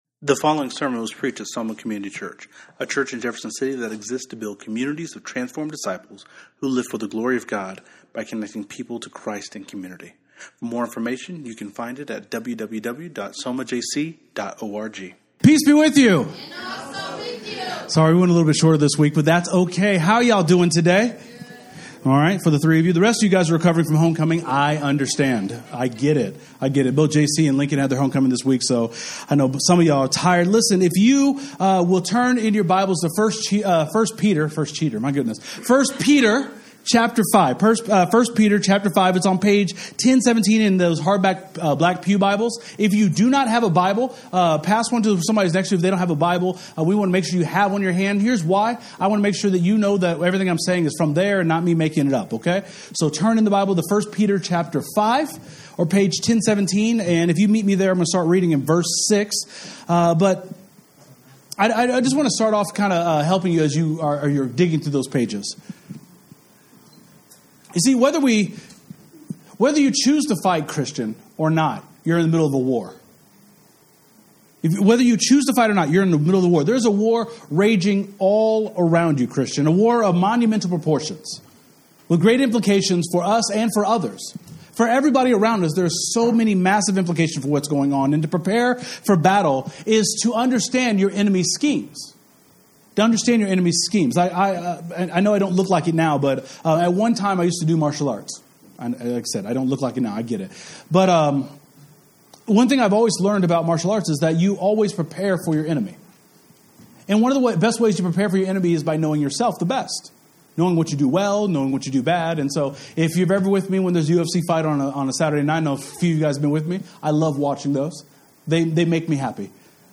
Bible Text: 1 Peter 5:6 – 9 | Preacher